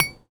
R - Foley 45.wav